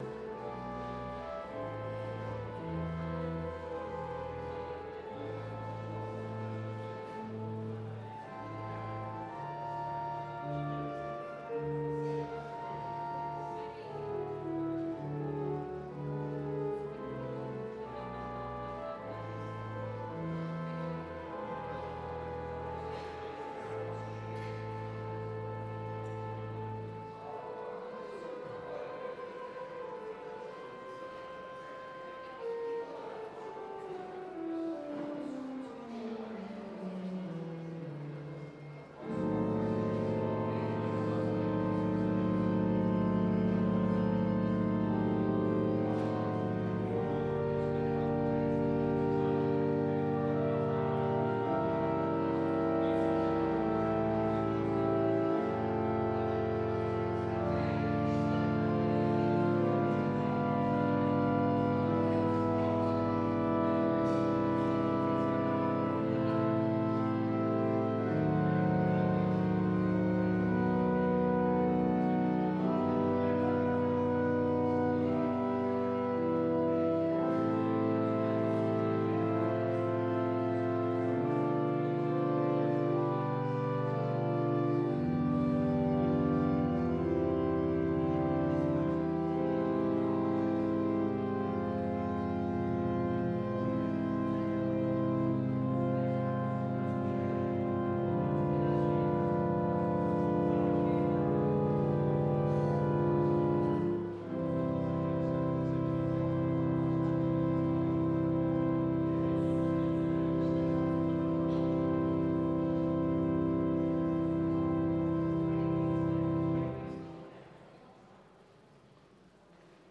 Sermon Only Audio